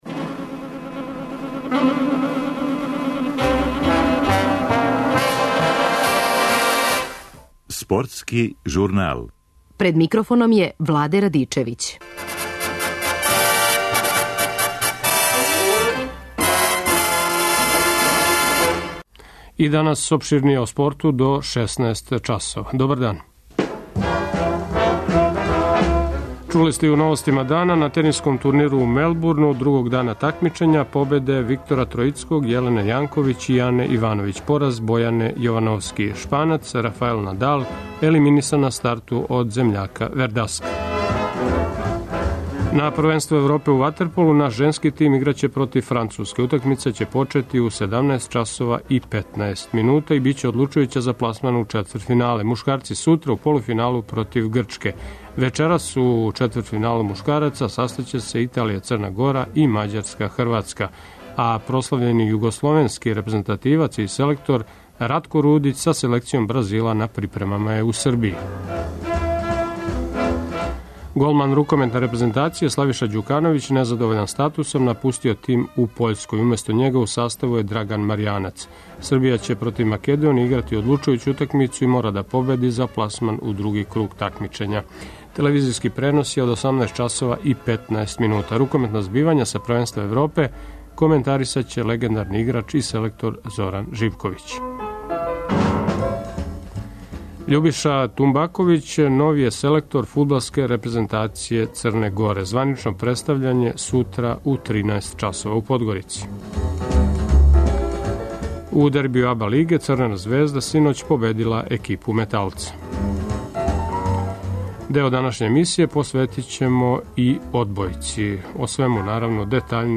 Рукометна збивања са првенства Европе коментарише легендарни играч и селектор Зоран Живковић.